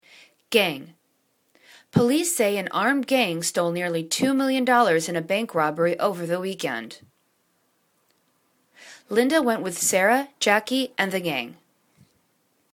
gang    /gang/    n